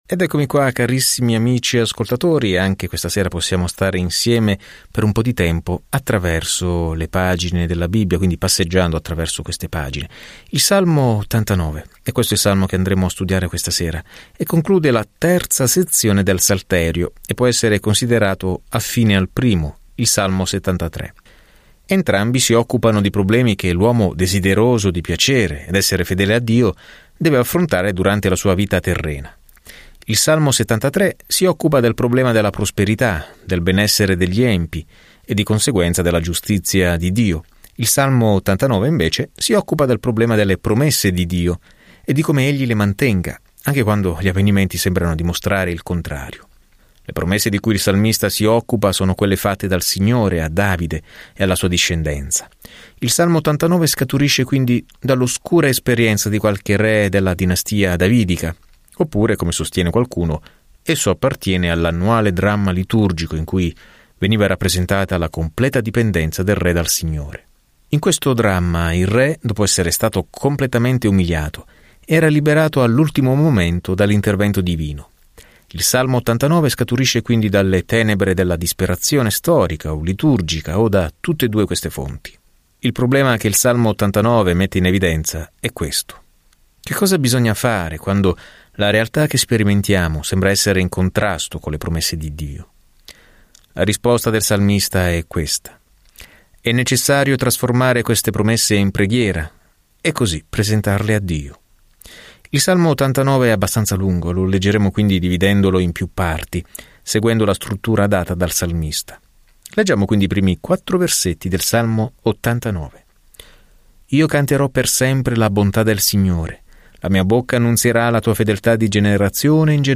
Scrittura Salmi 89:1-52 Giorno 46 Inizia questo Piano Giorno 48 Riguardo questo Piano I Salmi ci danno i pensieri e i sentimenti di una serie di esperienze con Dio; probabilmente ognuno originariamente messo in musica. Viaggia ogni giorno attraverso i Salmi mentre ascolti lo studio audio e leggi versetti selezionati della parola di Dio.